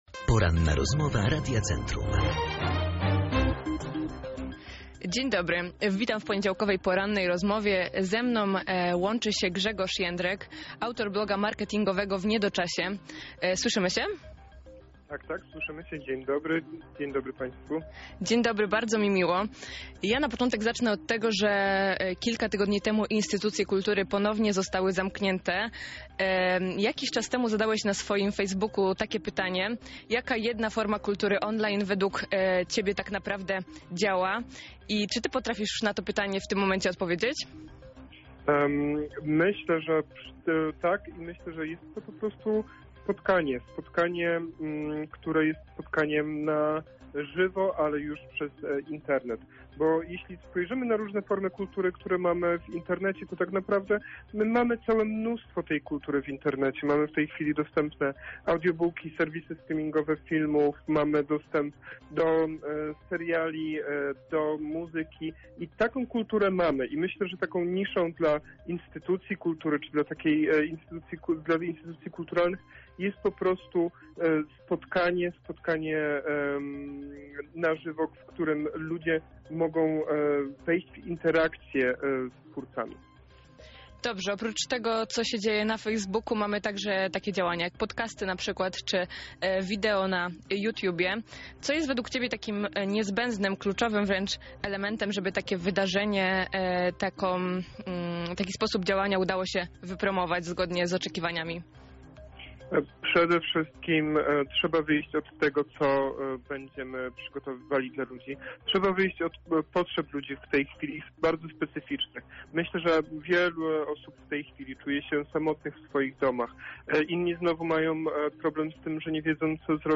Poranna rozmowa